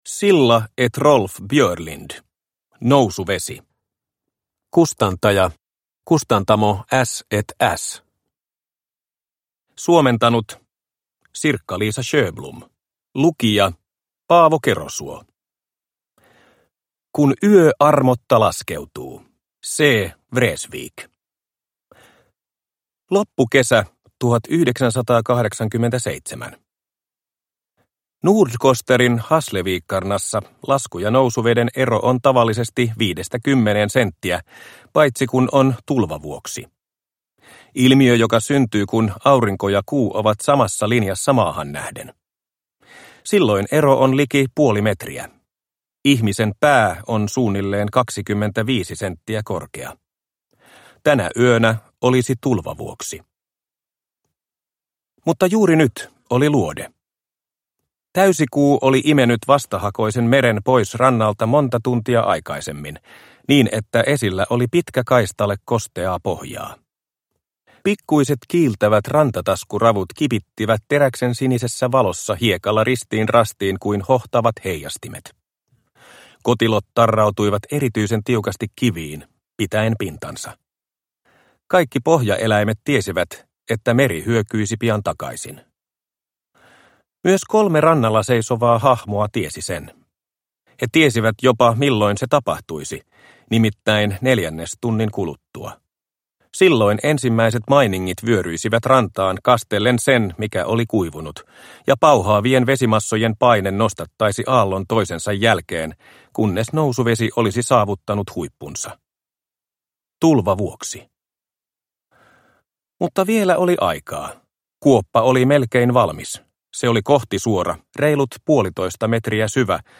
Nousuvesi – Ljudbok – Laddas ner